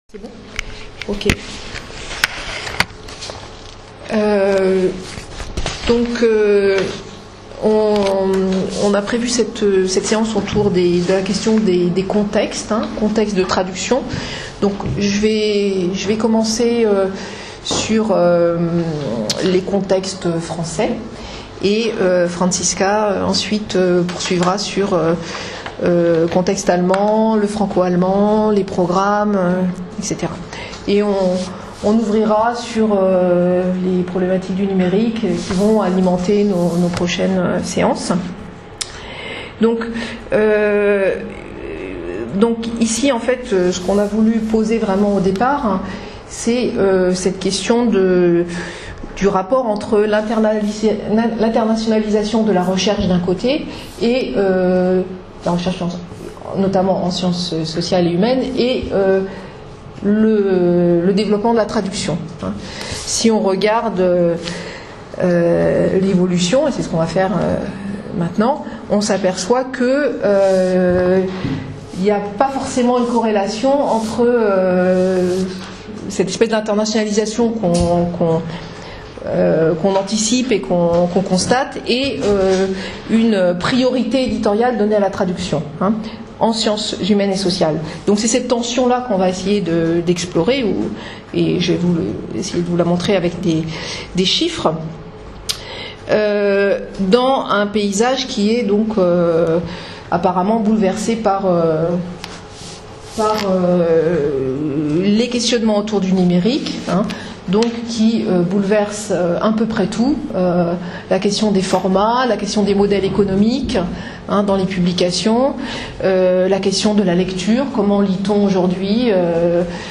L’enregistrement du cours d’introduction qui s’est tenu le 10 novembre 2016 peut être écouté ici: